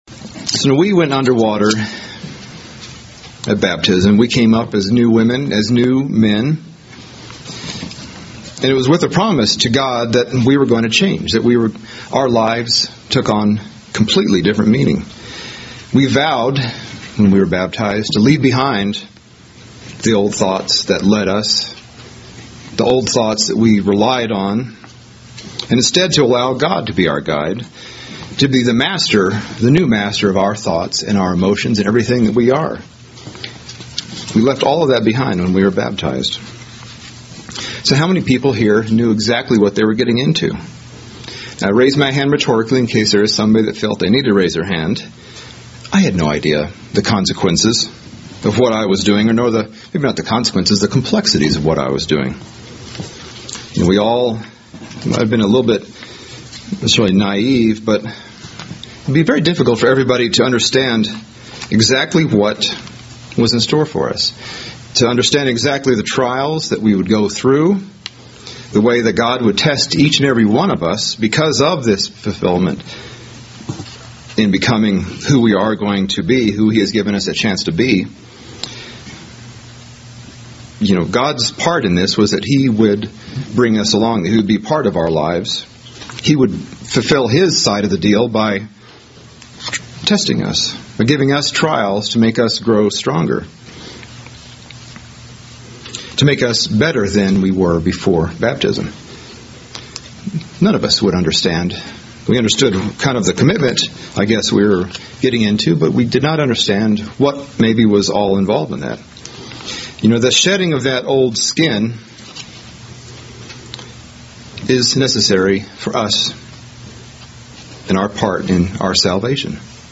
Sermons – Page 135 – Church of the Eternal God